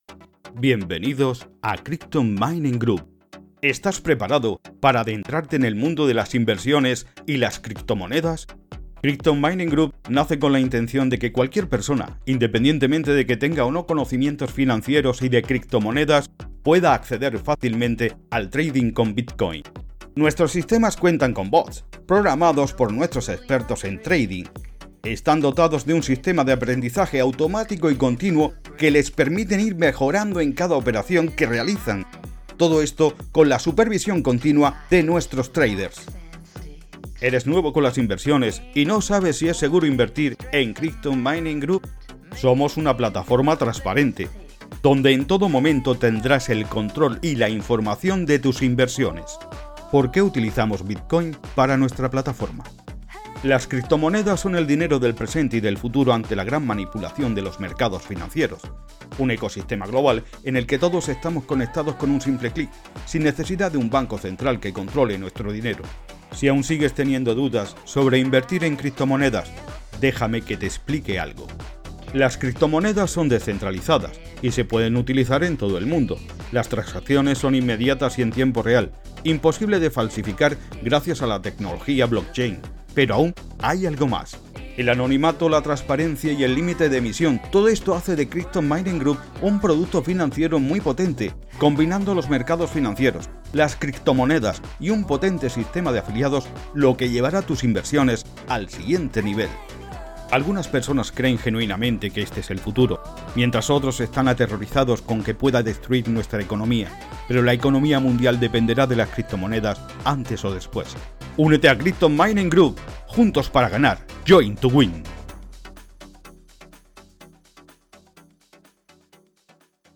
Sprechprobe: Industrie (Muttersprache):
I am an active professional speaker, native in Spanish, with my own studio.